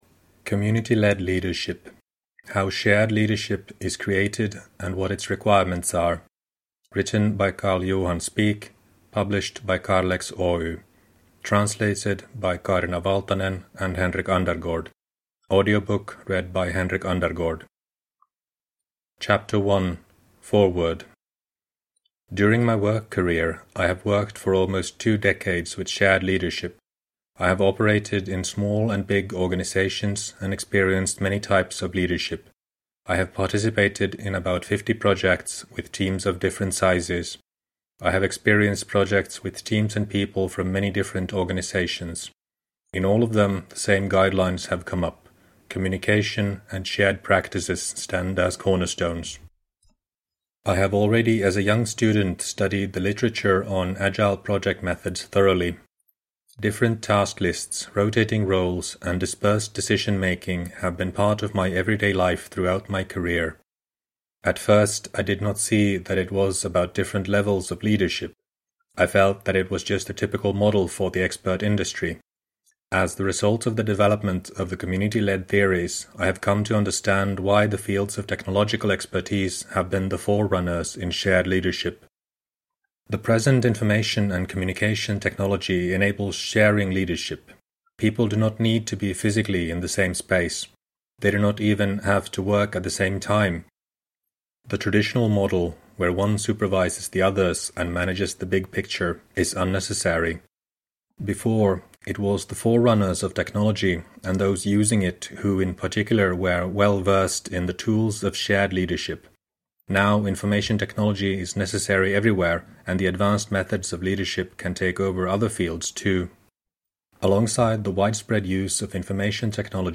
Community-Led Leadership : How Shared Leadership Is Created and What Its Requirements Are – Ljudbok – Laddas ner